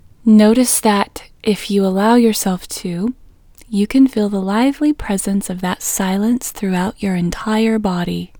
WHOLENESS English Female 6